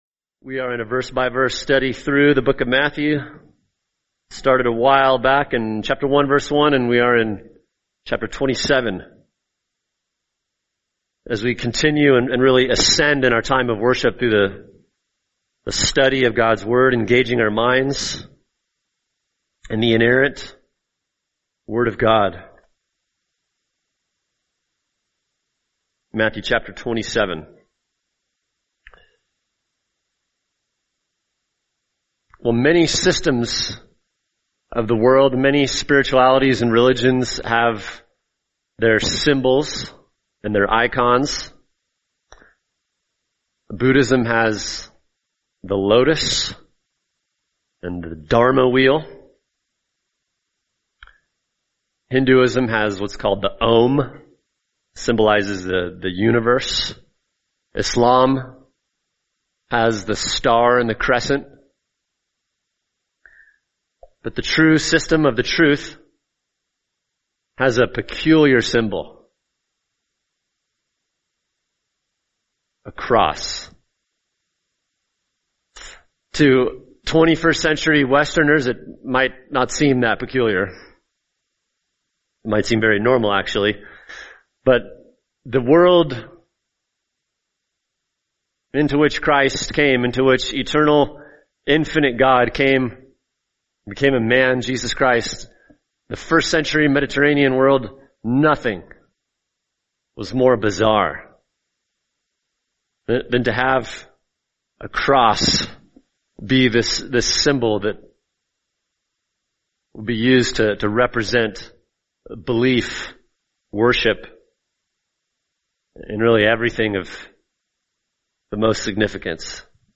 [sermon] Matthew 27:32-34 The Creator Can’t Carry a Cross | Cornerstone Church - Jackson Hole